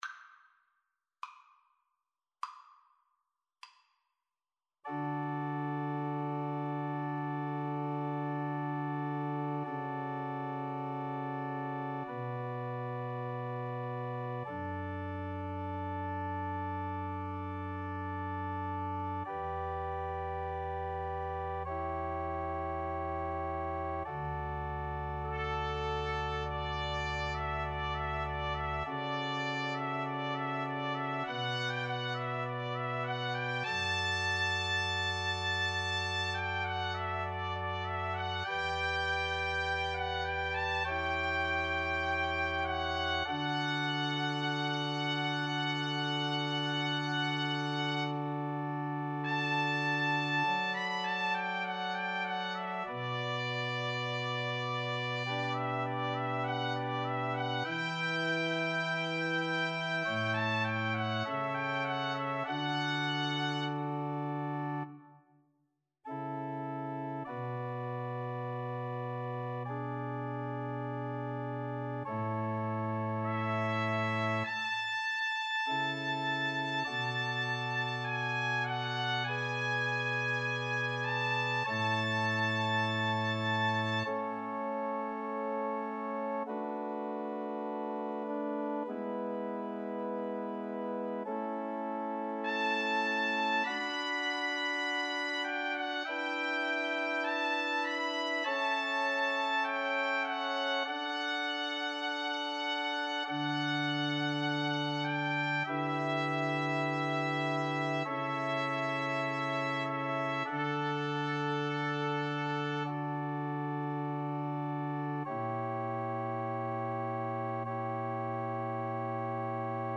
Play (or use space bar on your keyboard) Pause Music Playalong - Piano Accompaniment Playalong Band Accompaniment not yet available transpose reset tempo print settings full screen
D major (Sounding Pitch) (View more D major Music for Soprano Voice )
4/4 (View more 4/4 Music)
Largo
Classical (View more Classical Soprano Voice Music)
handel_eternal_source_SOP_kar1.mp3